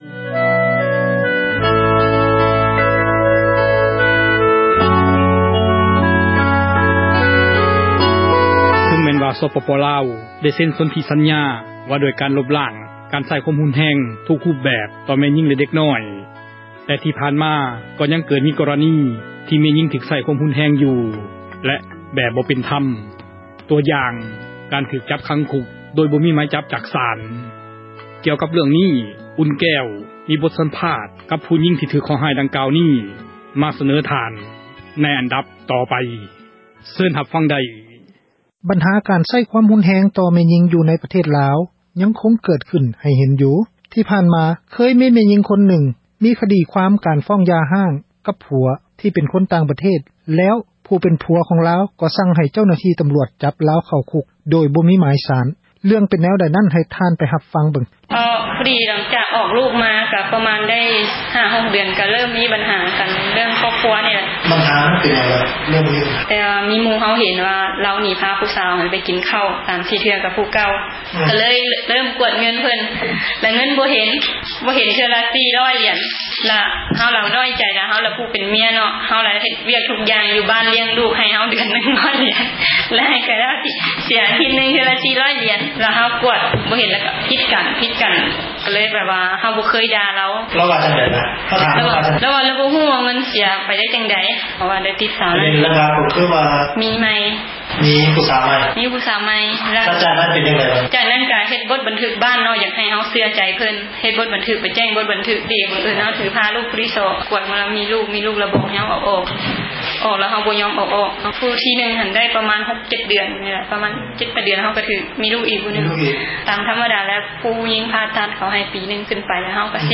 ບົດສຳພາດ